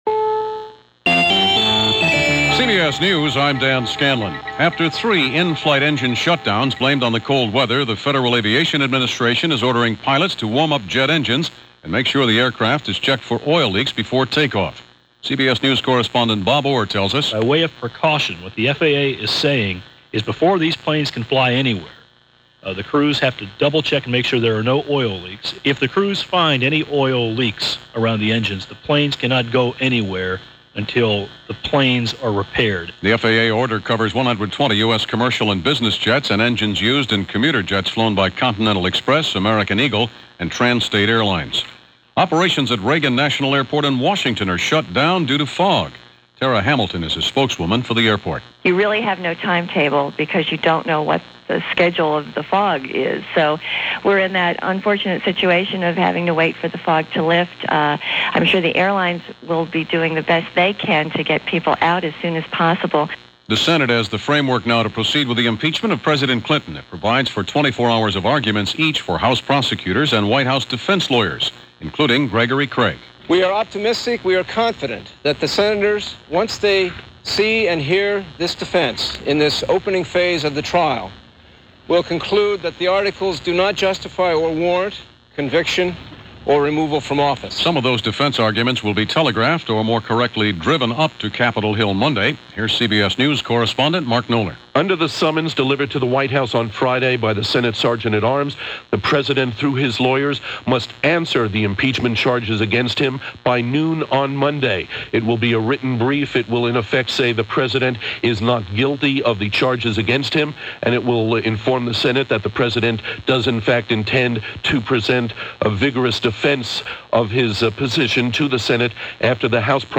All that, and much-much more including President Clinton’s Saturday Address, along with CBS Hourly News for January 9, 1999.